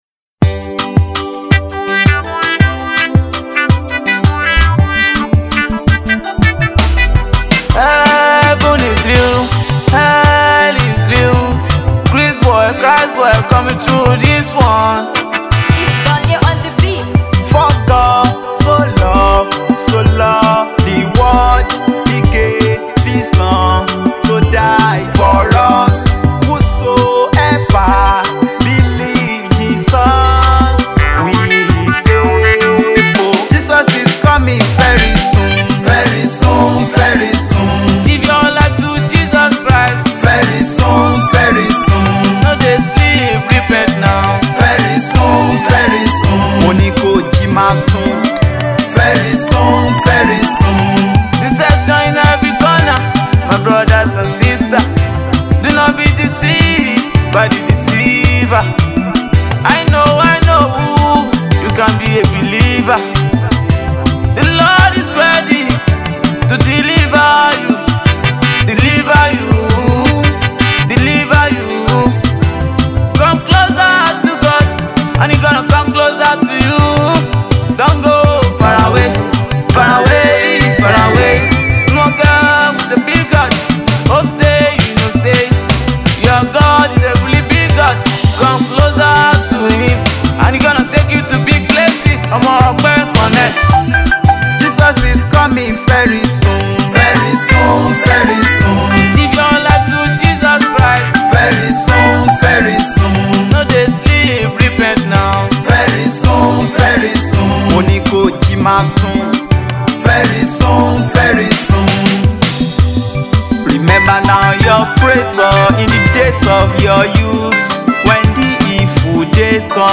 Epic gospel song